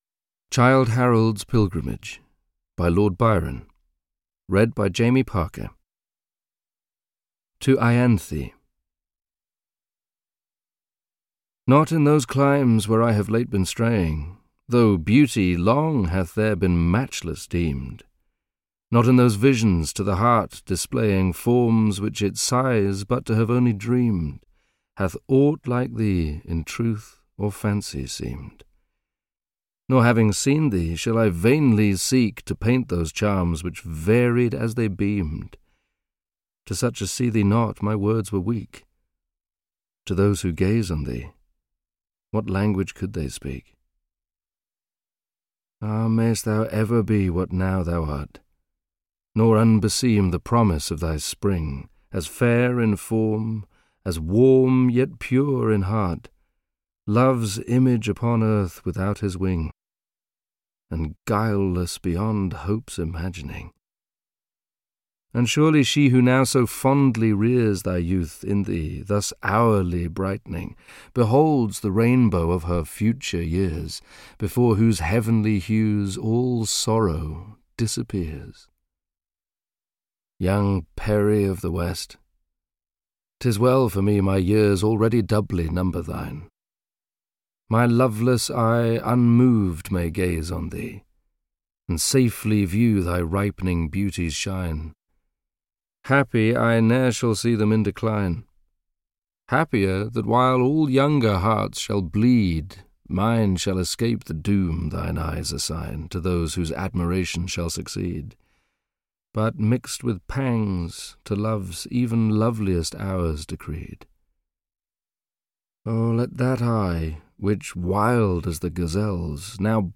Childe Harold’s Pilgrimage (EN) audiokniha
Ukázka z knihy
• InterpretJamie Parker